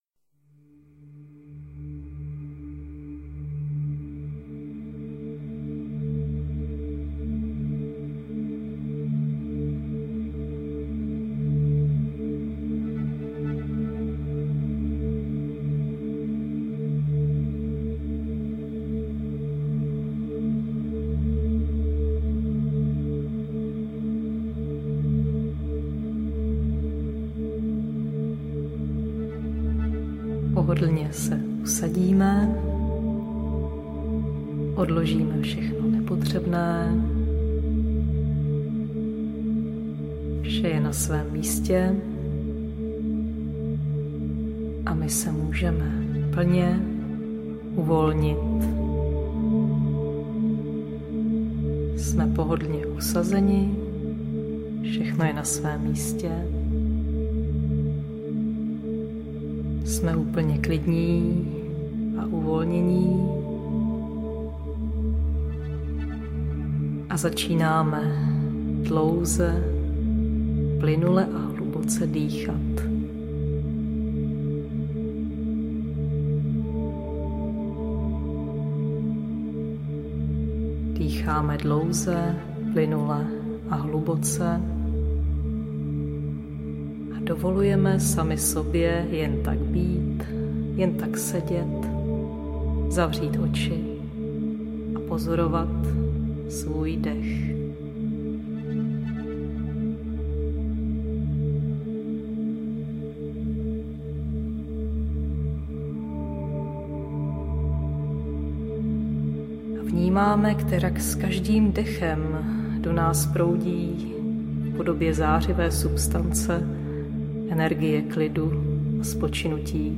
Na ostrově harmonie audiokniha
Ukázka z knihy